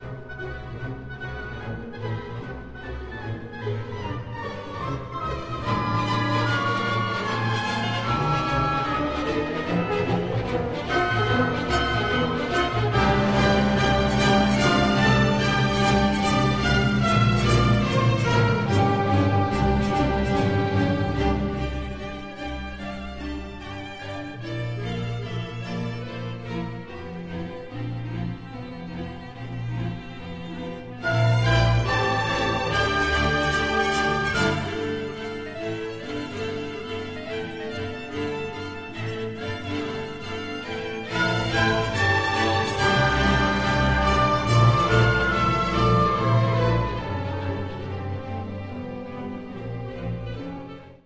Variace na slovenskou lidovou píseň pro velký orchestr